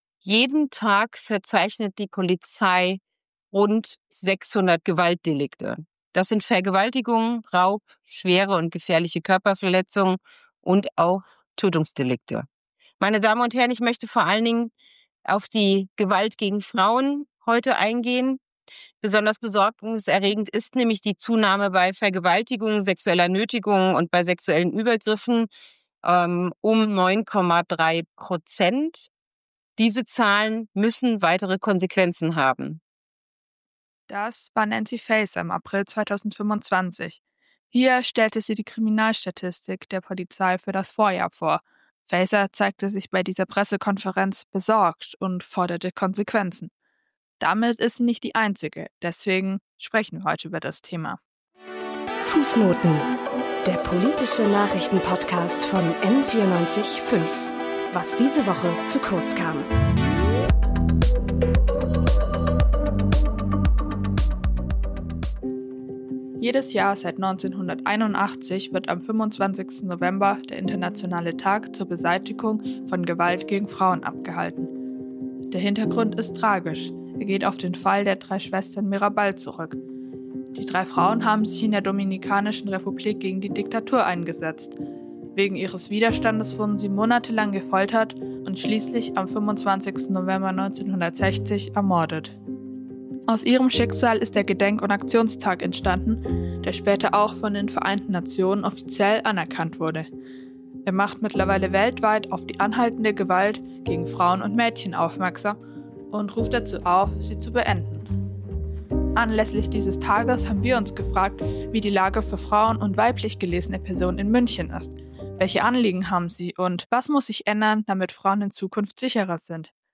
Was ist sexualisierte Gewalt und wie äußert sie sich im Alltag von Frauen? Welche Unterstützung bieten Beratungsstellen wie der Frauennotruf München für Betroffene? Und wie können wir als Gesellschaft dazu beitragen, Gewalt gegen Frauen zu verhindern und Frauen zu stärken? Dafür sprechen wir in dieser Interviewfolge